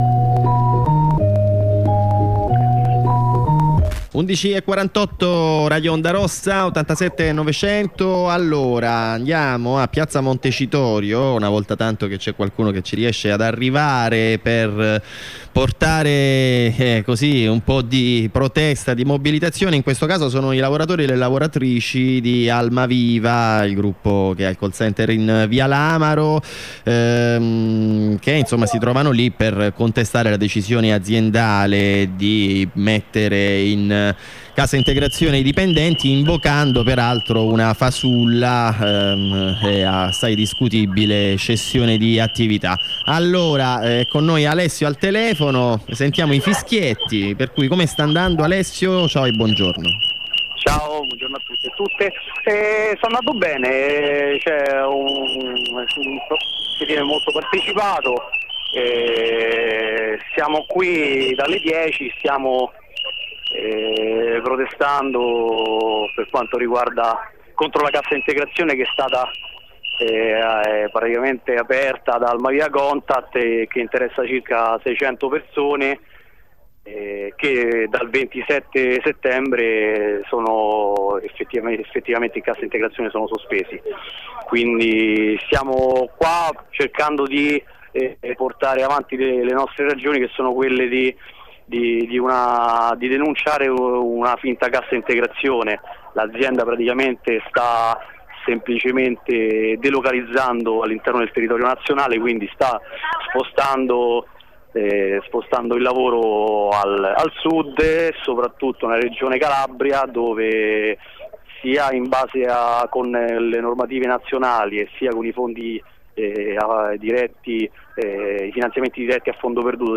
237° puntata: collegamento con una lavoratrice di Almaviva. Un intervento che esprime rabbia per la situazione che circa 2900 persone si trovano a vivere, ovvero il concreto rischio di licenziamento, e che invita a partecipare al presidio previsto dalle ore 14 sotto il Mise.
Collegamento con un co-redattore di Corrispondenze Operaie che si trova a Parigi: ci offre un quadro sintetico della mobilitazione contro la Loi Travail e della situazione che da quasi tre mesi sta caratterizzando tutta la Francia.